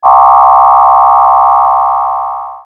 RetroGamesSoundFX / Alert / Alert14.wav
Alert14.wav